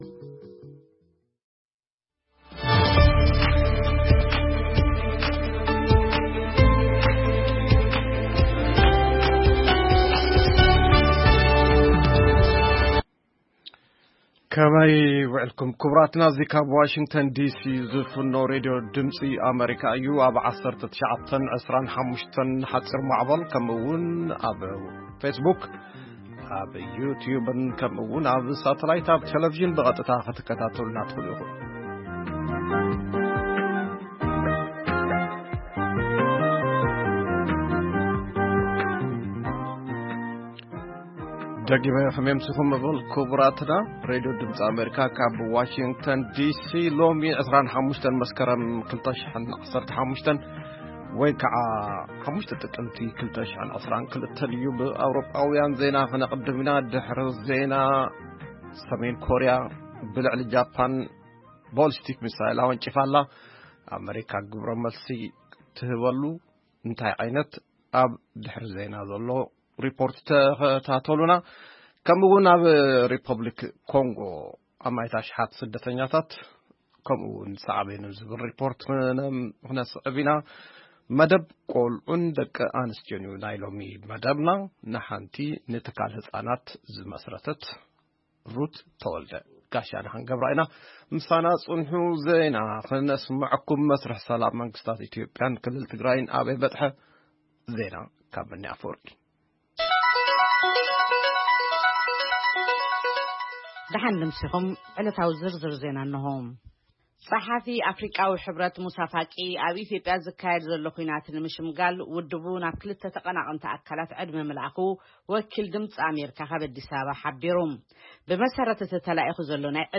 ቃለ መጠይቕ